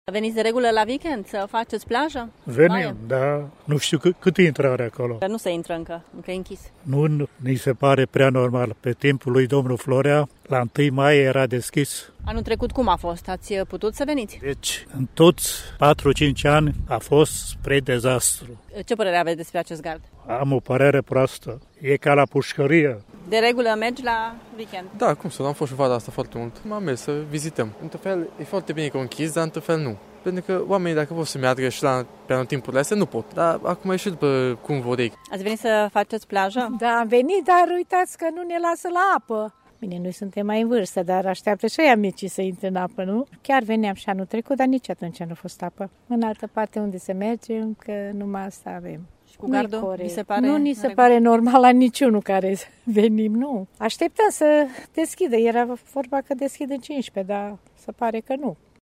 Unii târgumureșeni au venit azi la Week-end pregătiți pentru plajă și relaxare.